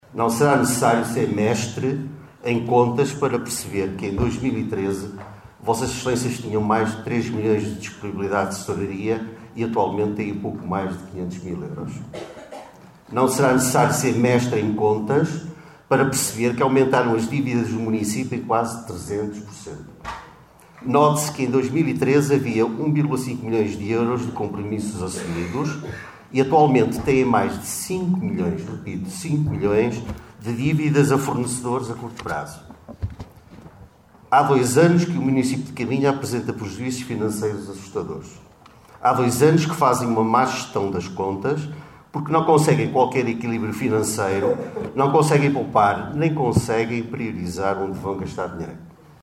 Excertos da última Assembleia Municipal.